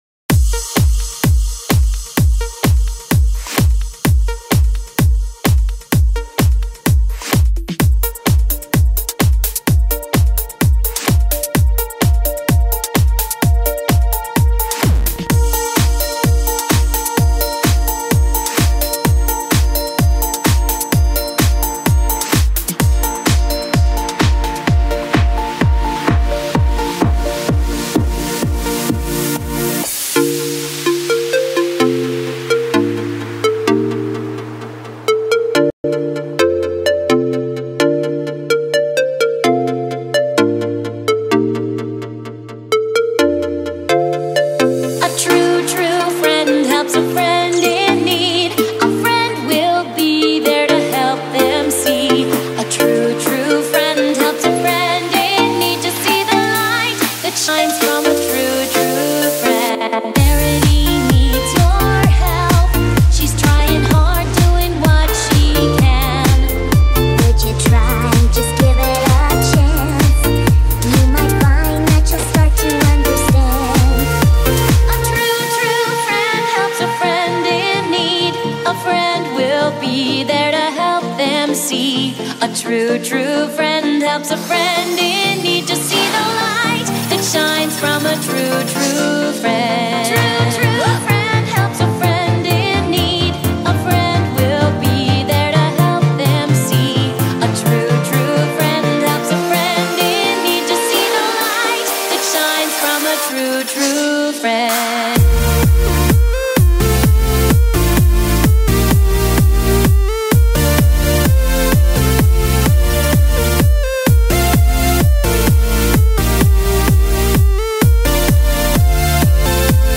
Bpm: 128